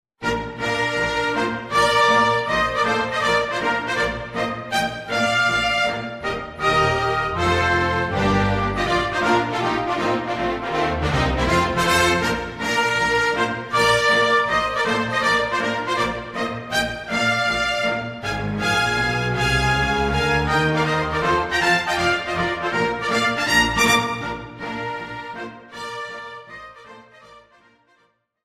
Woodwind, Brass and Strings